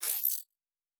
pgs/Assets/Audio/Sci-Fi Sounds/Weapons/Additional Weapon Sounds 2_3.wav at 7452e70b8c5ad2f7daae623e1a952eb18c9caab4
Additional Weapon Sounds 2_3.wav